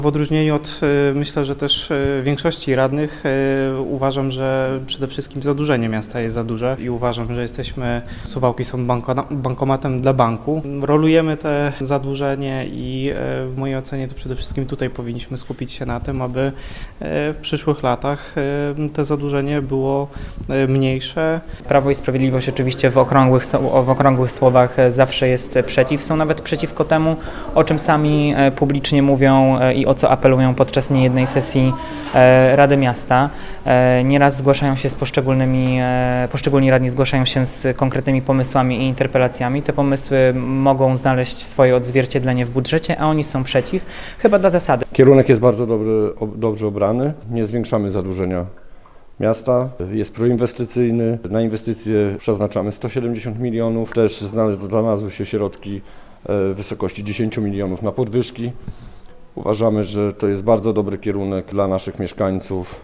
O przyszłorocznym budżecie mówią Piotr Rydzewski z Prawa i Sprawiedliwości, Filip Olszewski z Koalicji Obywatelskiej i Kamil Lauryn z klubu Razem dla Suwałk.
radni.mp3